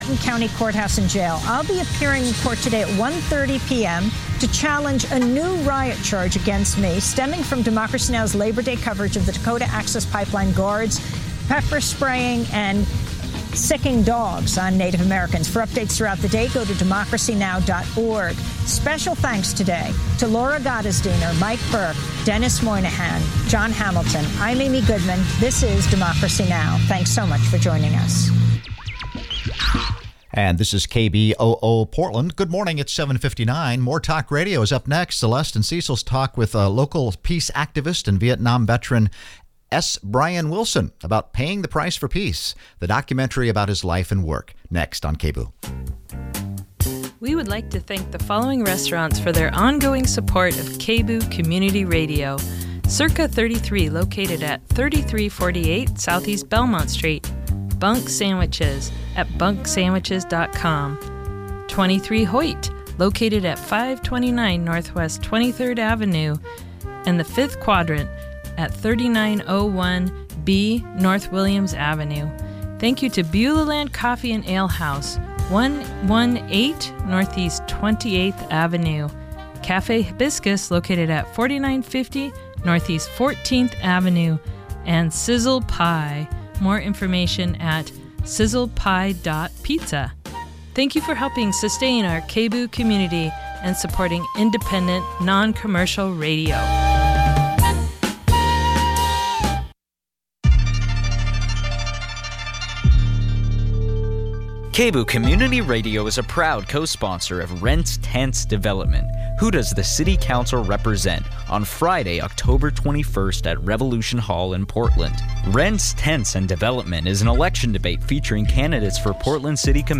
More Talk Radio